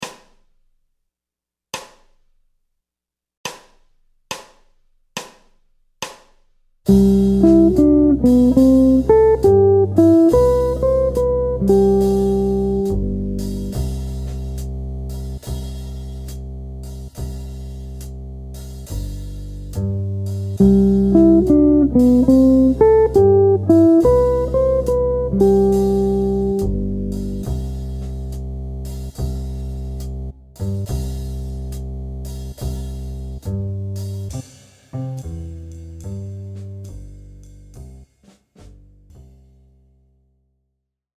C Utilisation de motifs d’arpèges en substitution diatonique (Em7. et Am7) sur l’accord de C Maj7